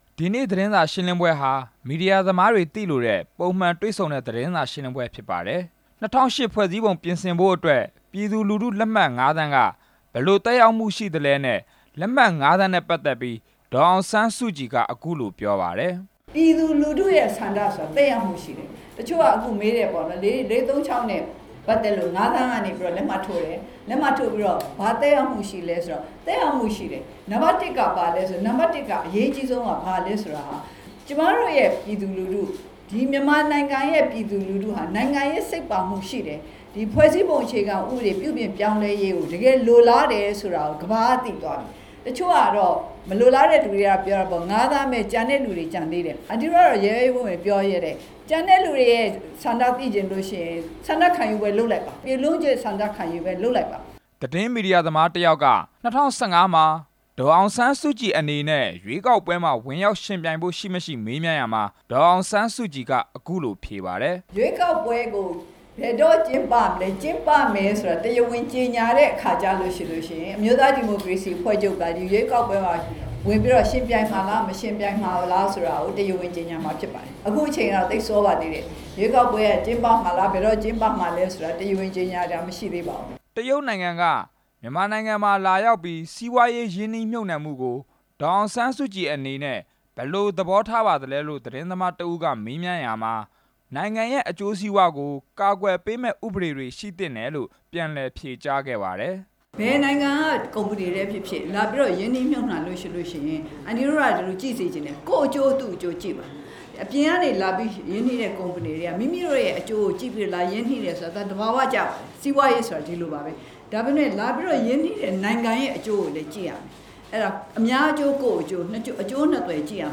အမျိုးသားဒီမိုကရေစီအဖွဲ့ချုပ် ဥက္ကဌ ဒေါ်အောင်ဆန်းစုကြည် သတင်းစာရှင်းလင်းပွဲ
ရန်ကုန်မြို့ အမျိုးသားဒီမိုကရေစီအဖွဲ့ချုပ်ရုံးမှာ အမျိုးသားဒီမိုကရေစီအဖွဲ့ချုပ် ဥက္ကဌ ဒေါ်အောင်ဆန်းစုကြည်က သတင်းမီဒီယာသမားတွေနဲ့ တွေ့ဆုံပြီး သတင်းမီဒီယာတွေရဲ့ မေးခွန်းတွေကို ဖြေကြားခဲ့ပါတယ်။